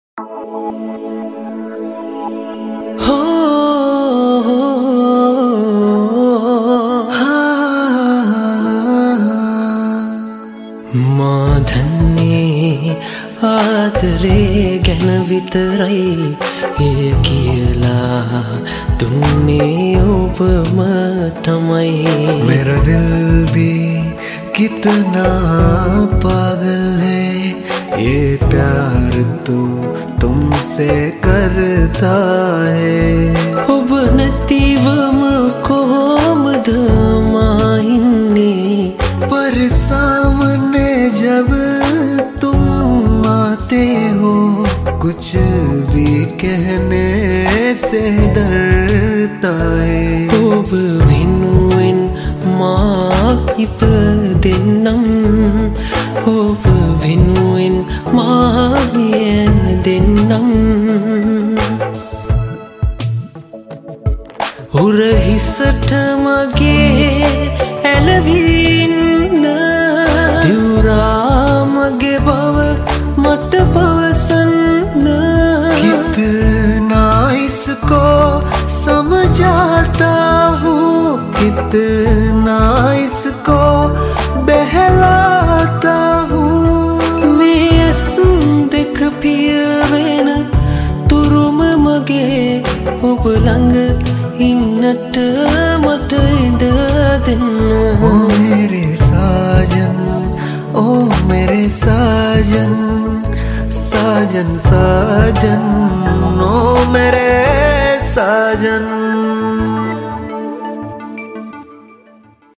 Mix Cover